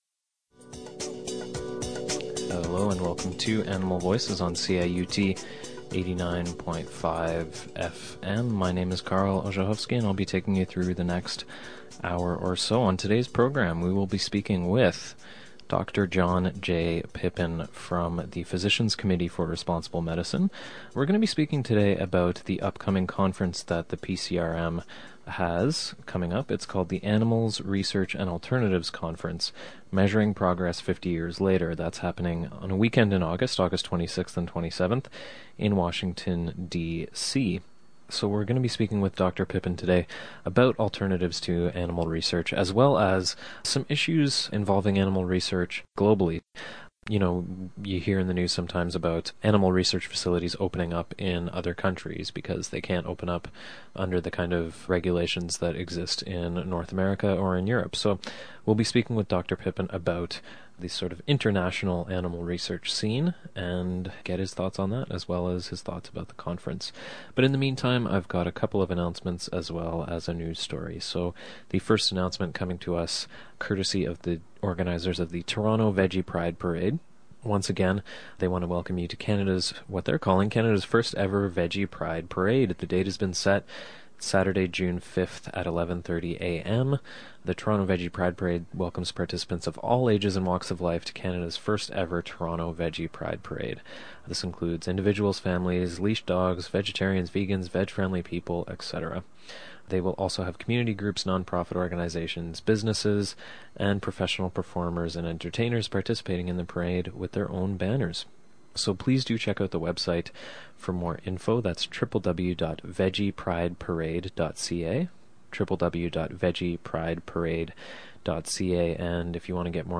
Interesting interview with former animal researcher